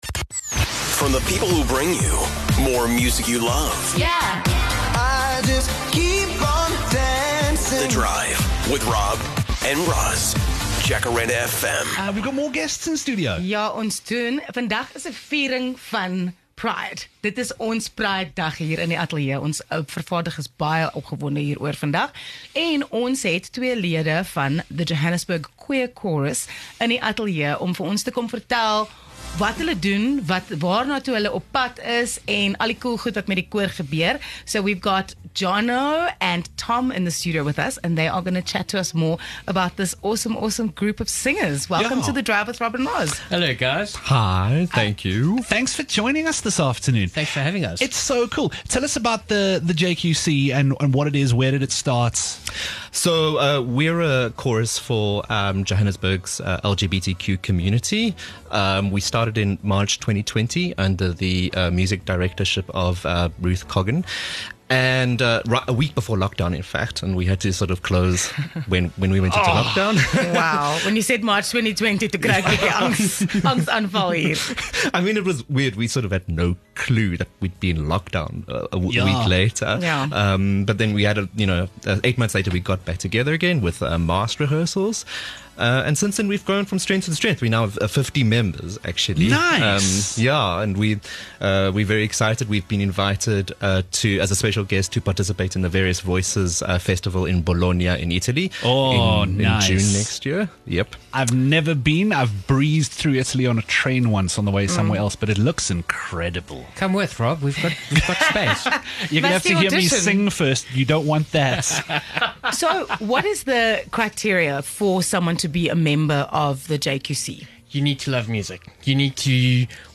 27 Oct The Johannesburg Queer Chorus comes in for a chinwag!